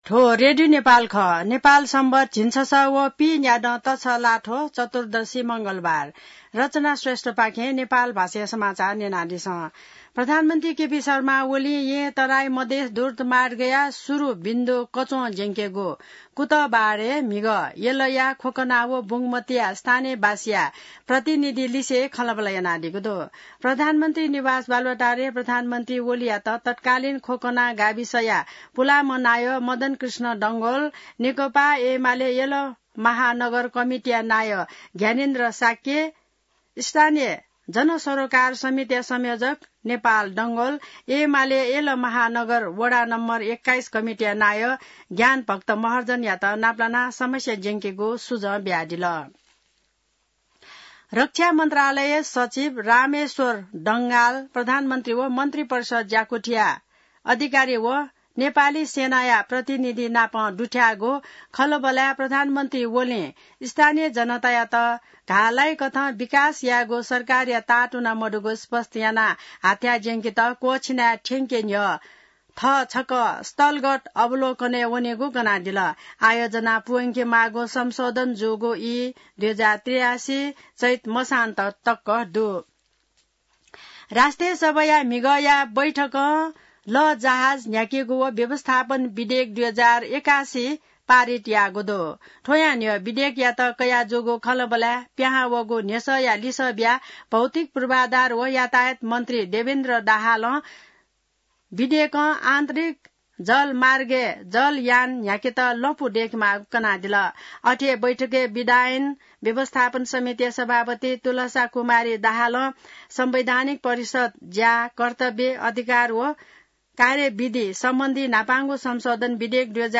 नेपाल भाषामा समाचार : २७ जेठ , २०८२